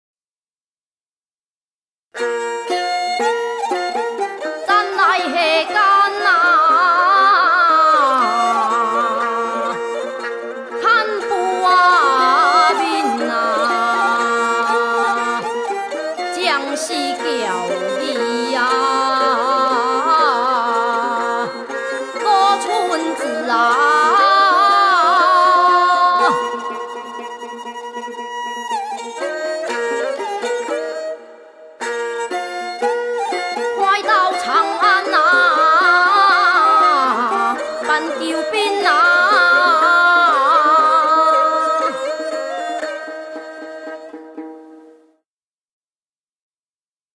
福路戲曲唱腔【緊中慢】 | 新北市客家文化典藏資料庫